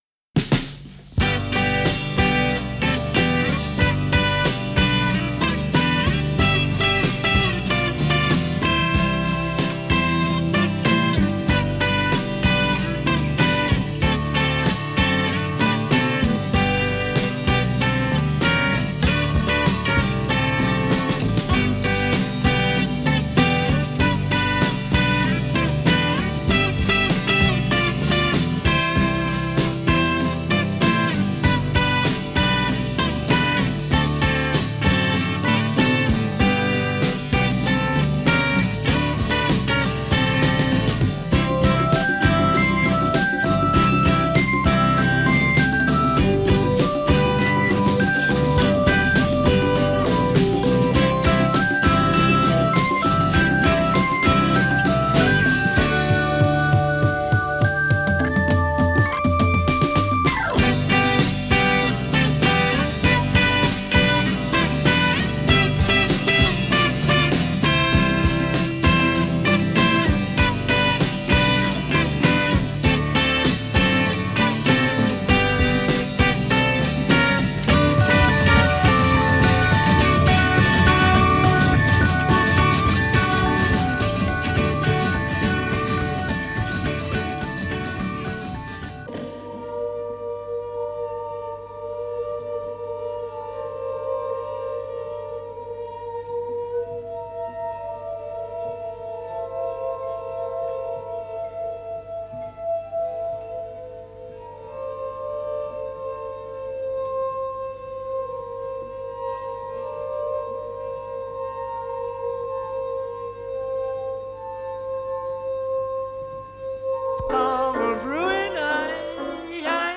it’s another instrumental
that summery organ
the classical dissonance), and musique-concrete